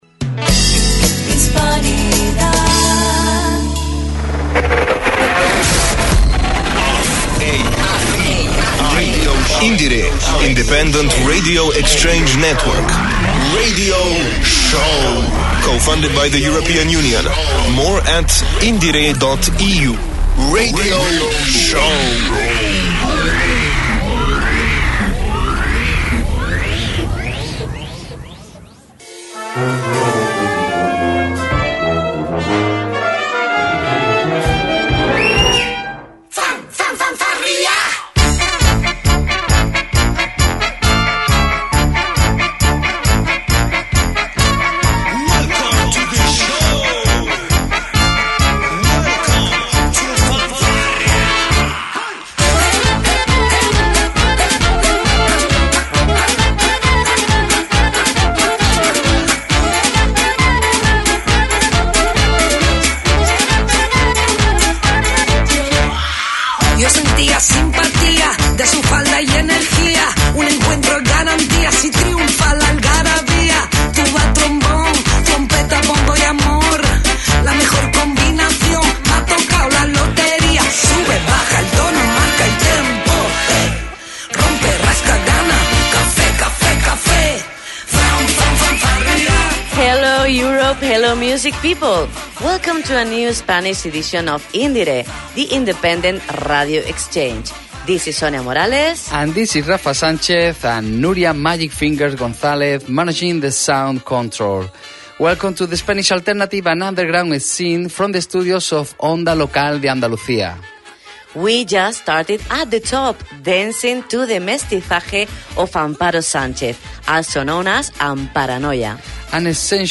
Powerful songs, amazing music and several genres